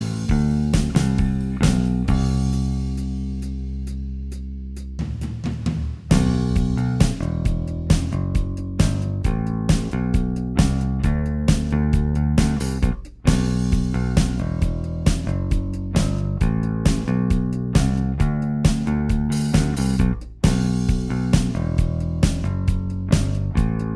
Minus Guitars Pop (1980s) 3:23 Buy £1.50